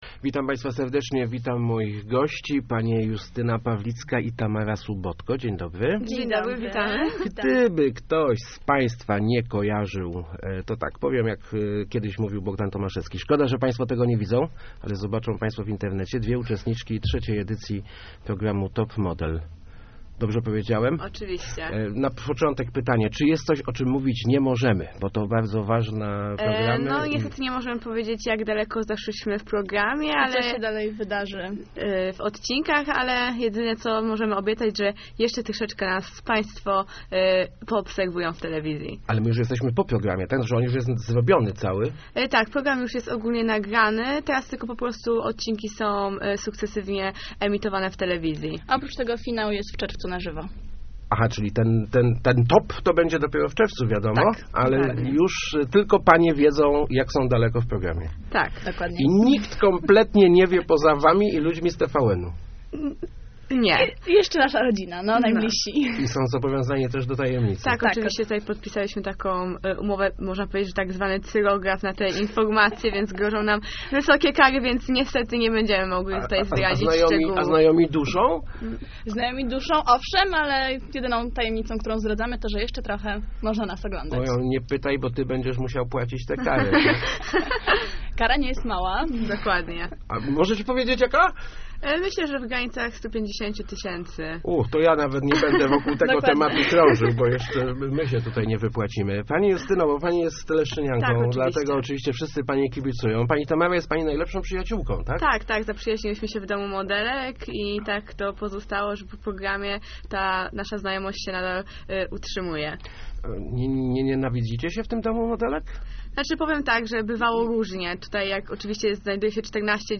uczestniczki programu Top Model. Dziewczyny przyznają, że wiele scen było reżyserowanych, zwłaszcza żeby wydobyć emocje.